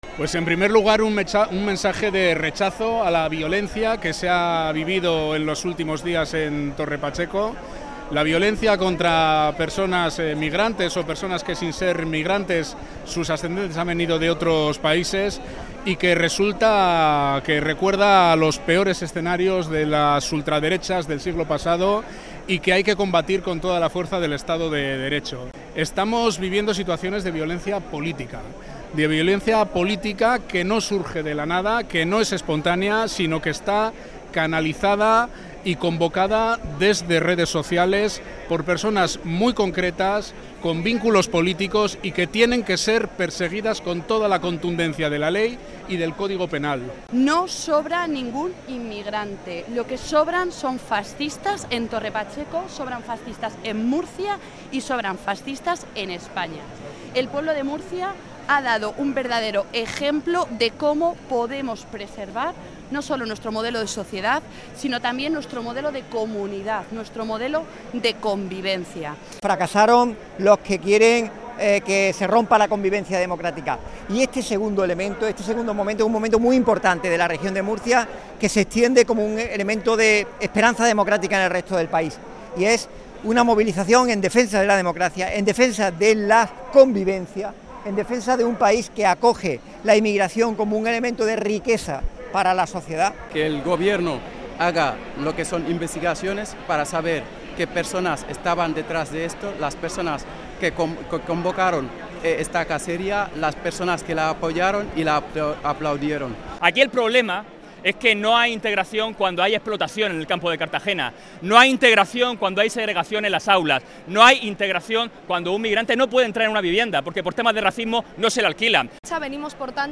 Sonidos manifestacion antirracista Murcia